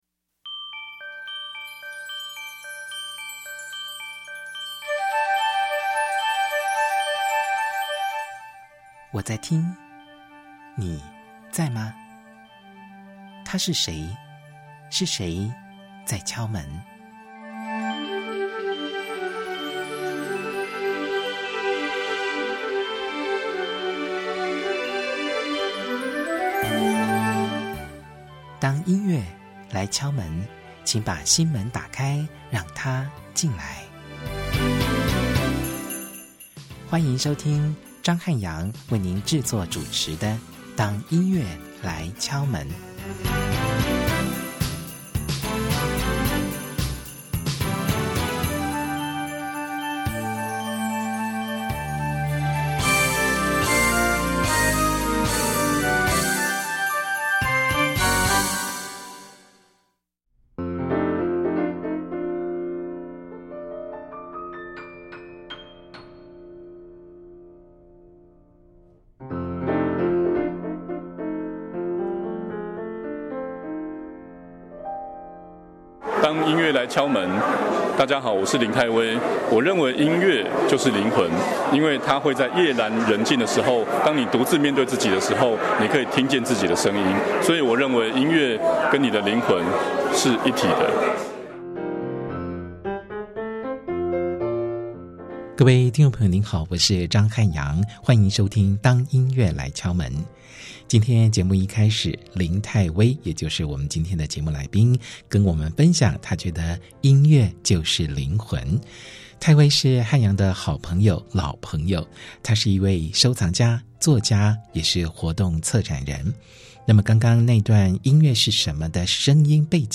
台中市政府客家事務委員會在東勢客家文化園區舉辦《留下台灣第一聲音∼曲盤裡肚?客家愛情音樂同日常》特展，7月13日開幕當天，我到現場採訪，仔細參觀之餘，決定以專題報導的方式錄製成一集節目，將這項活動推薦給大家。 這項特展透過蟲膠唱片與留聲機，帶領我們跟著旋律回到100多年前，聽見那個時代的聲音與愛情。